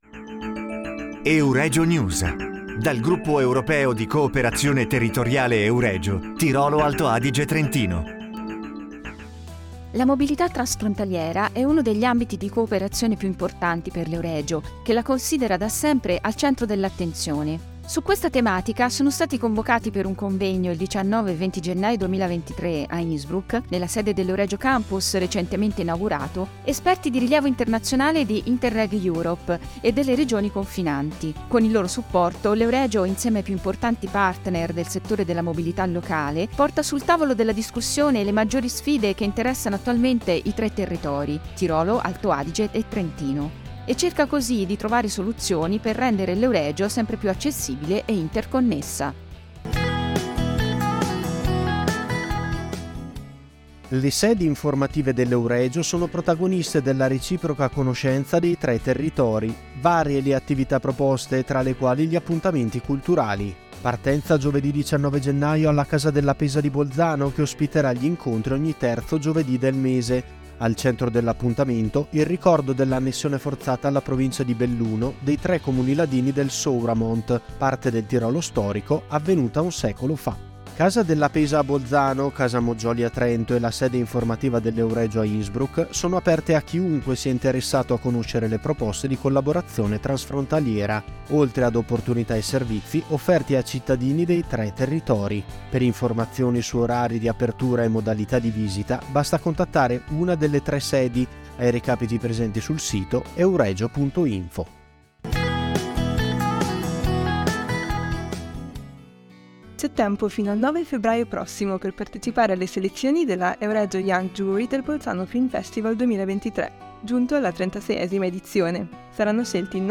Un convegno sul tema della mobilità transfrontaliera; gli appuntamenti e le visite nelle tre sedi dell'Euregio; le selezioni per la giuria giovanile del Bolzano Film Festival: sono i temi della nuova puntata di Euregio news - Dal Gruppo Europeo di cooperazione territoriale Tirolo-Alto Adige-Trentino, il programma radiofonico realizzato dall'Ufficio stampa della Provincia autonoma di Trento in collaborazione con il Segretariato generale dell'Euregio.